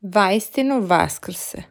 Vaistinu vaskrse (tap for pronunciation) or